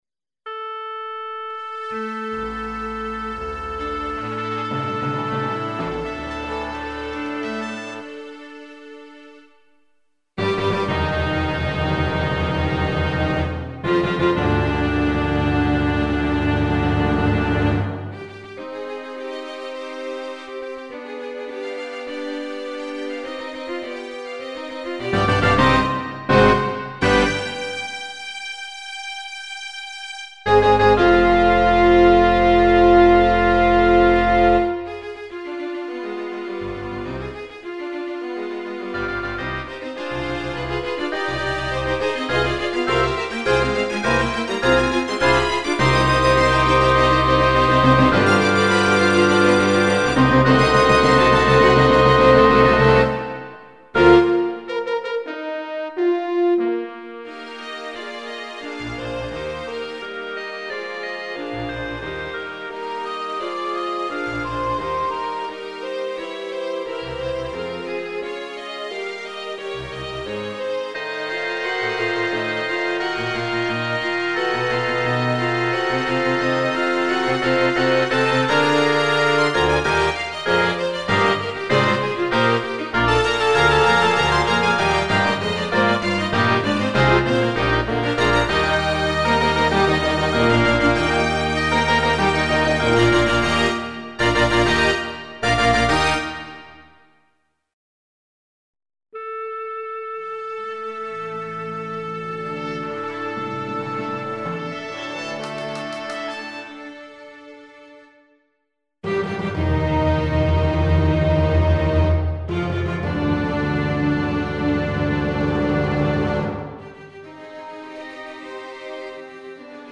Каждый из предложенных файлов содержит два варианта звучания одной и той же мидяхи- сначала на софто-отстое, потом с моего рабочего SF2.
Между ними пауза в 1 секунду.. чтоб отдышаться от каки. :-)
НИКАКОЙ дополнительной обработки не производилось.